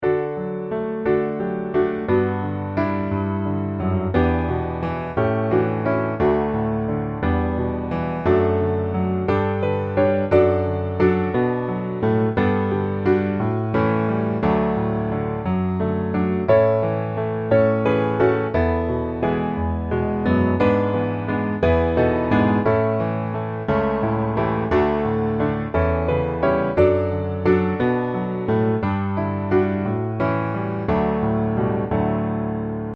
D Majeur